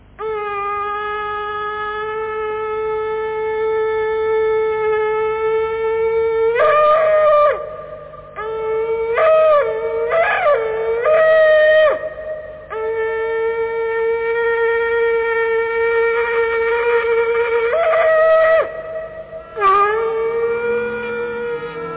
Shofar blowing on Rosh Hashana: The שברים-תרועה tone during the תקיעות דמיושב is blown as one tone, all other שברים-תרועה tones are blown with a short break.
made in 1988 for a film, therefore music is heard at the end. This is the way we used to blow.